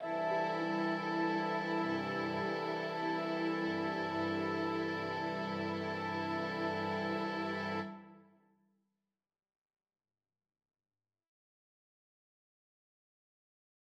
구스타프 말러의 "대지의 노래" (1909)의 "고별"의 마지막 마디는 6화음의 표현력과 모호성을 최대한 활용한다.[22]